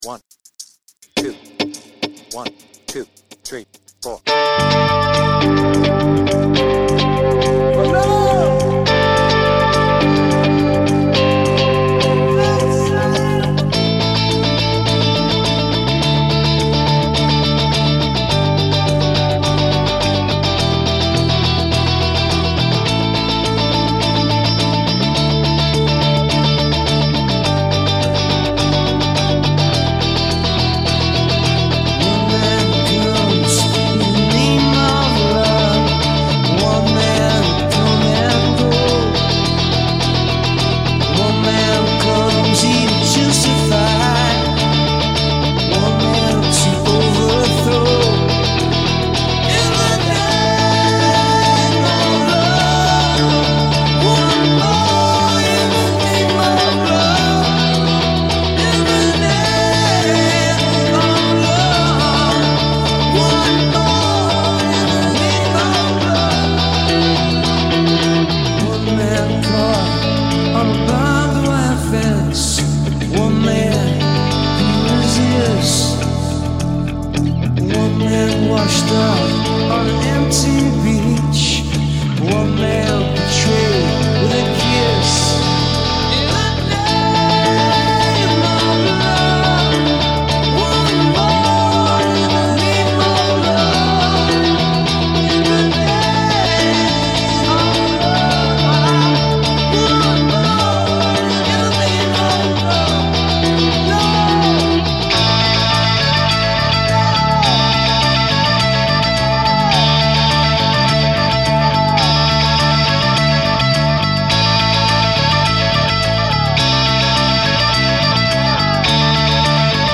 BPM : 105
Tuning : Eb
With Vocals